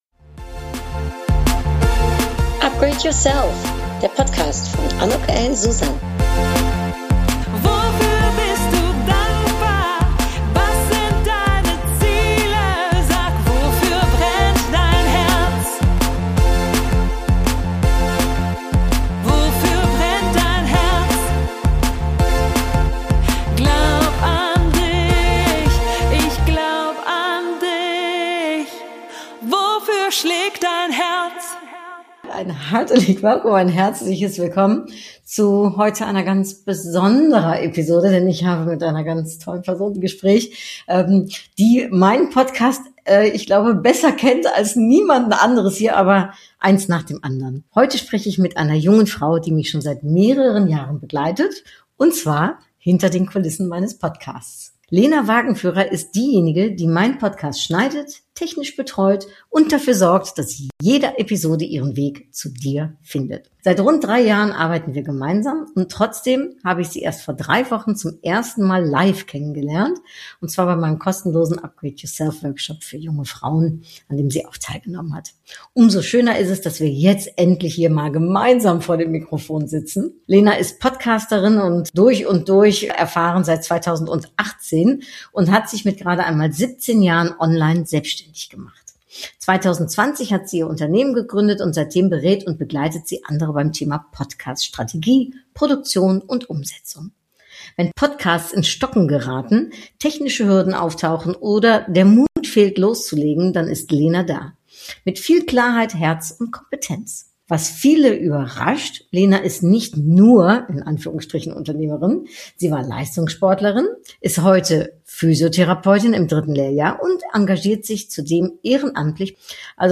Umso schöner ist es, dass wir heute endlich gemeinsam vor dem Mikrofon sitzen.